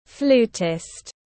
Flutist /ˈfluːtɪst/